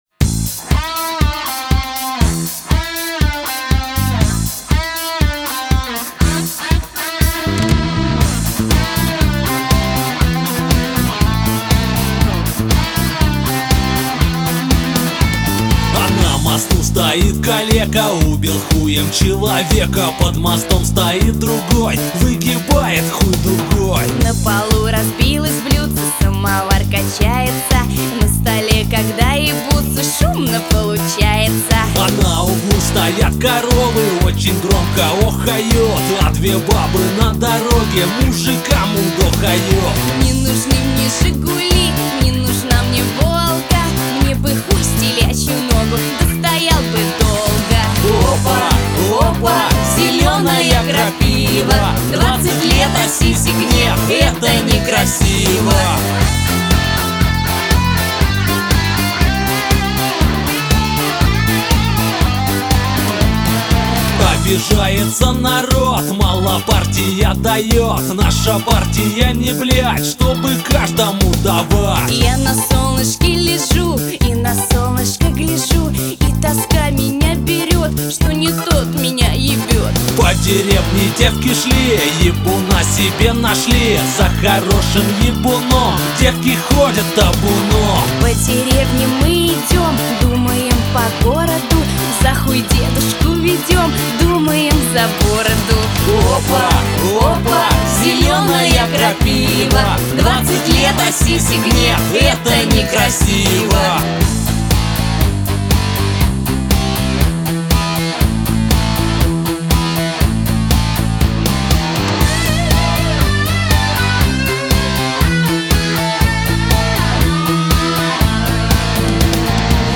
CHastushki___2.mp3